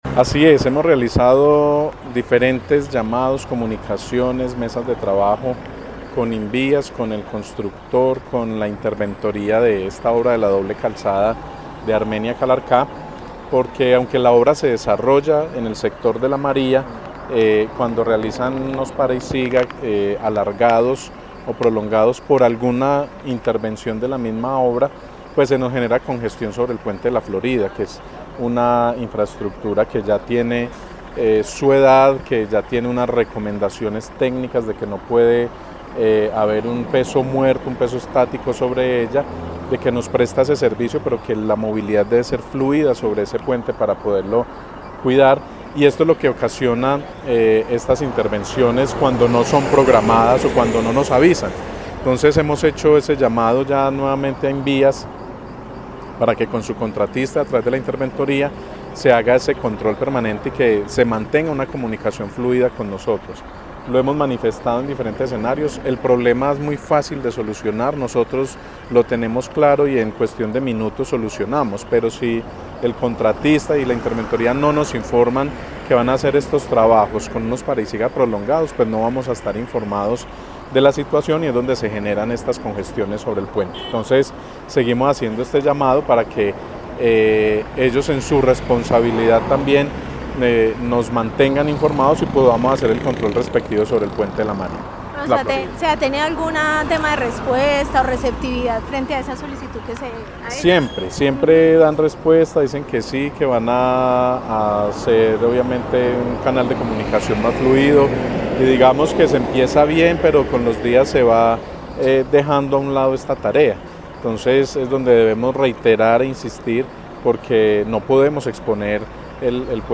Secretario de Tránsito de Armenia